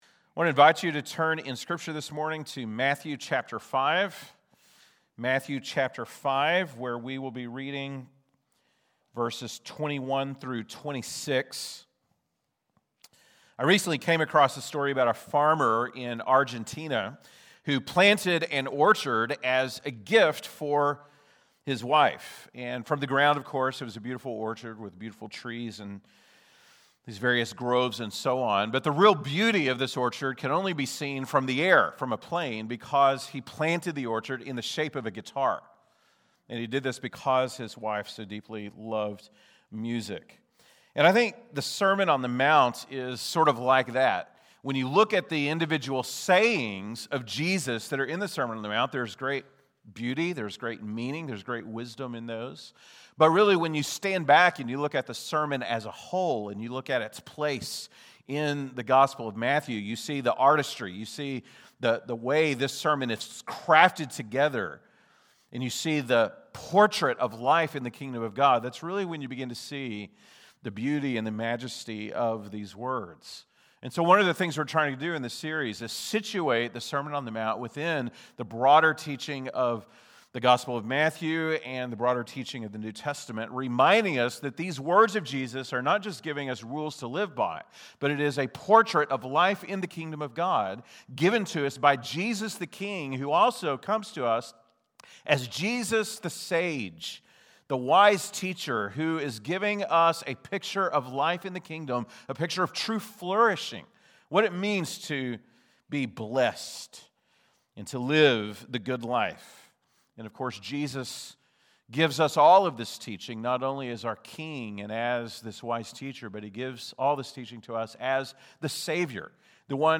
January 18, 2026 (Sunday Morning)